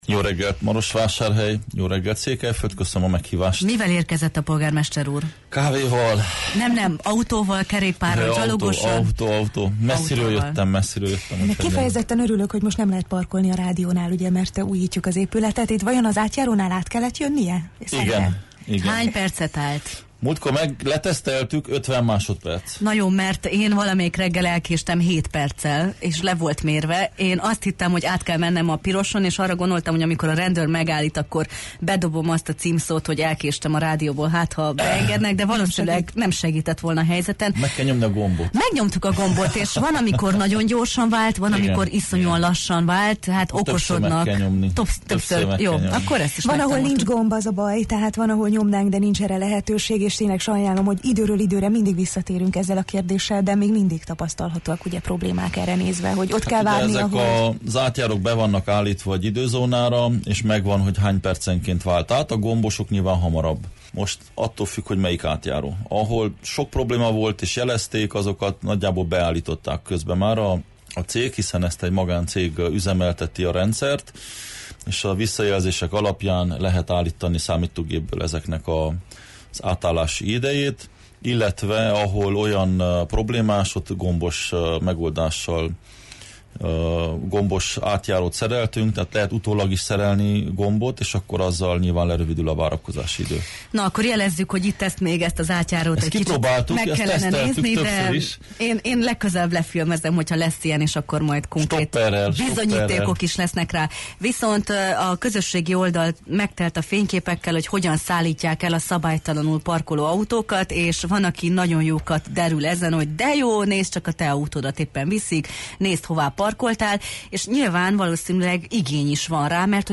A Jó reggelt, Erdély! című műsor vendége volt Soós Zoltán, Marosvásárhely polgármestere, aki a várost érintő legfontosabb kérdésekről beszélt. A beszélgetés során szó esett a városi közlekedés problémáiról, infrastrukturális beruházásokról is.